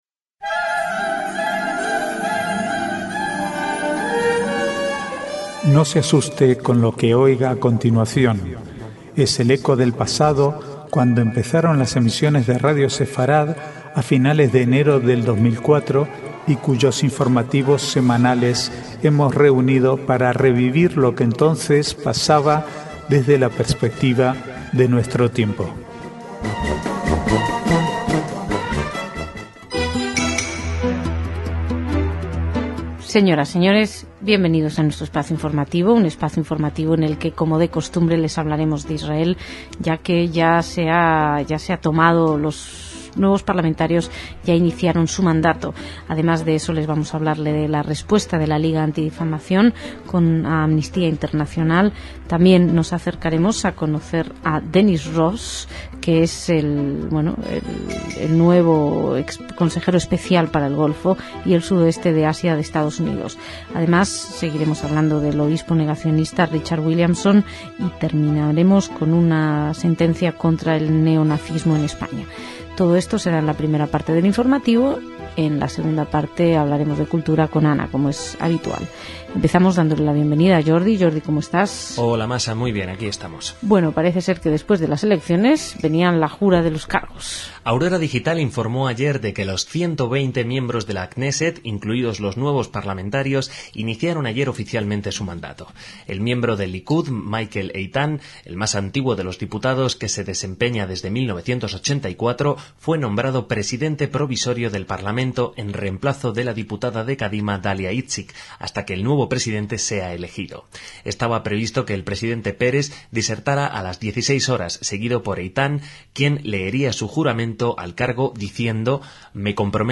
Archivo de noticias del 25 al 27/2/2009